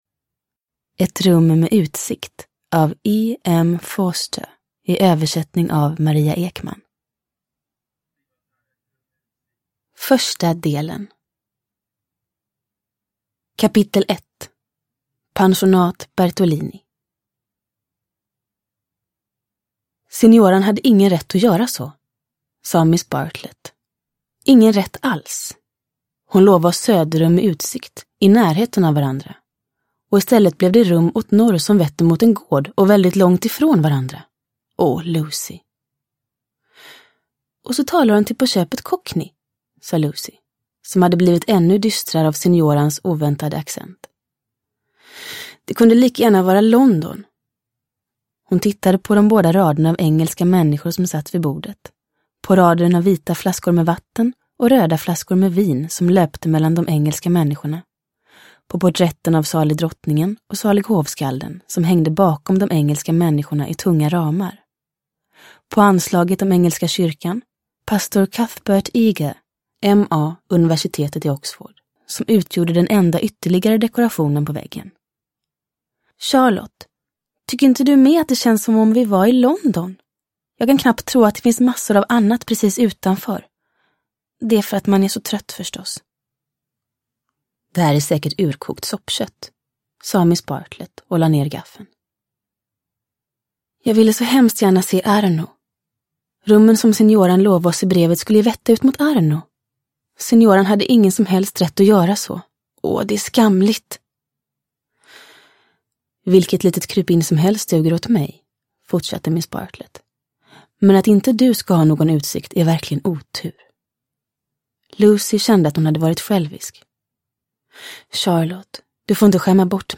Ett rum med utsikt – Ljudbok – Laddas ner
Uppläsare: Julia Dufvenius